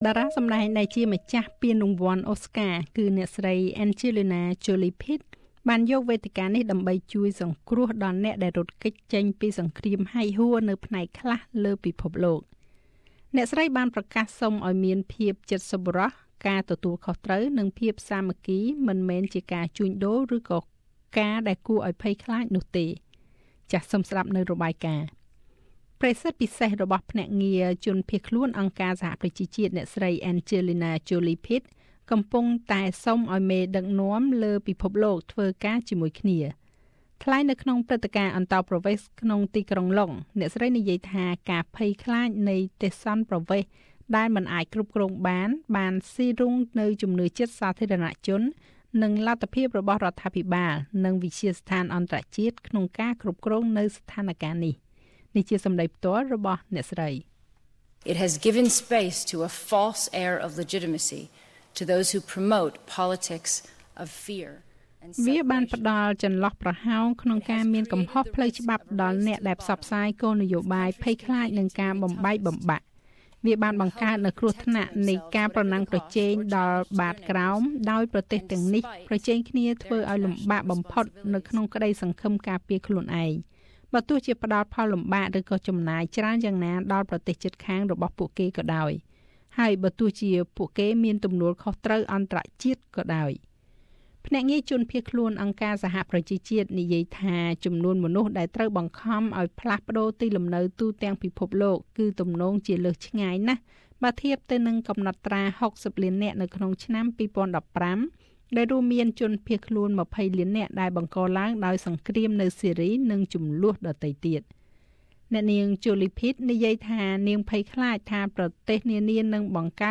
Special envoy for UN refugee agency Angelina Jolie speaks at BBC refugee event Source: AAP